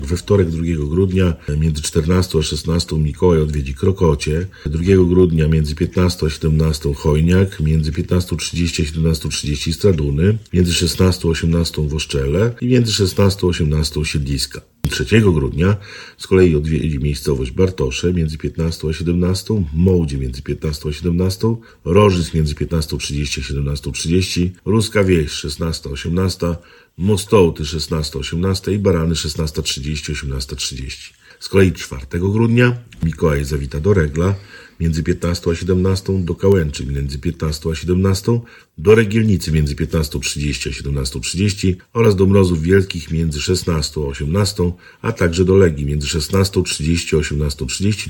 Jak mówi jej wójt – Tomasz Osewski, potrwają w sumie kilka dni.